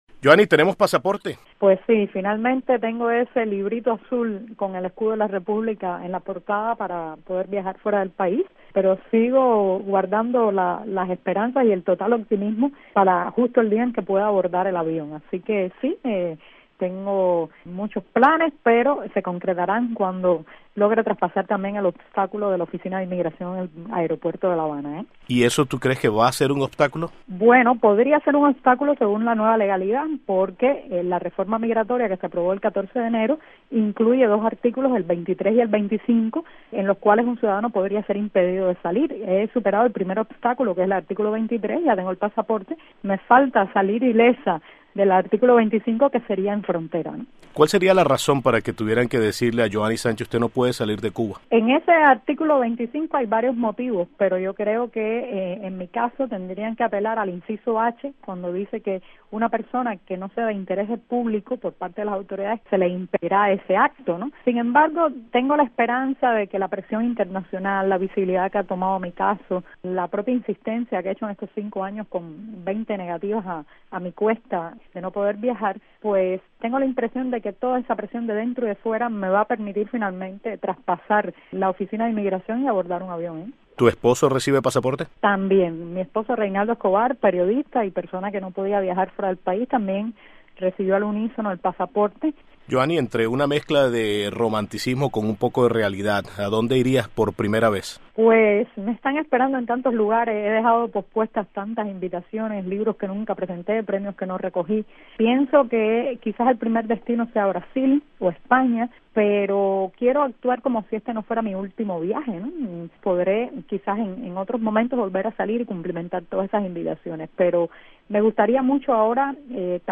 Entrevista a bloguera Joani Sánchez